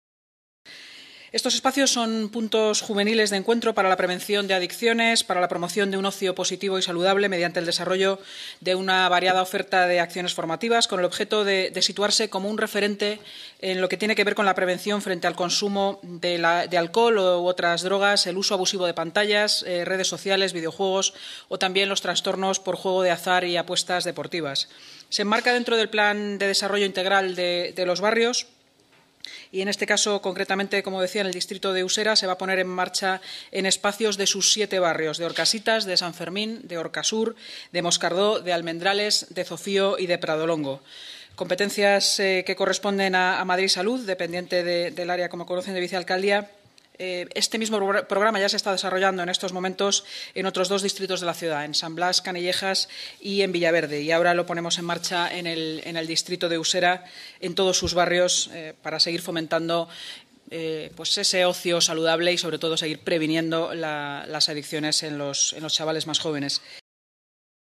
Nueva ventana:Inmaculada Sanz, vicealcaldesa, portavoz del Ayuntamiento y delegada de Seguridad y Emergencias